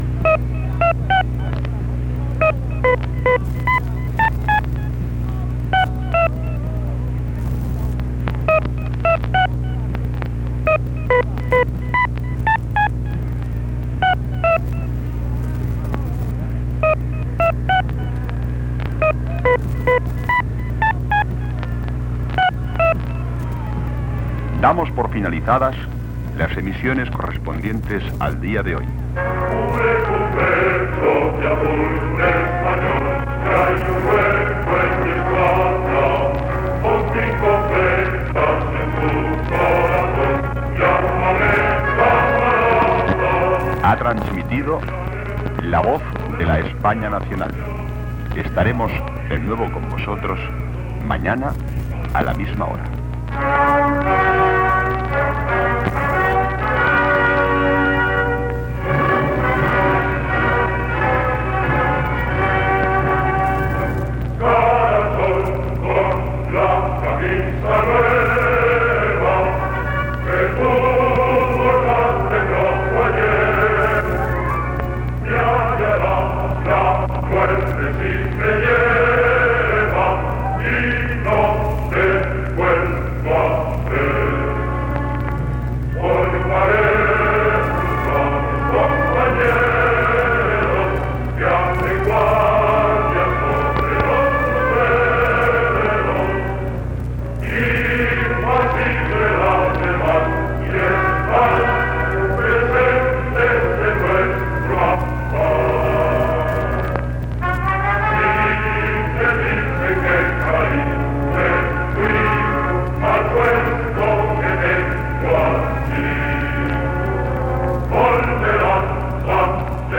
Tancament de l'emissió
FM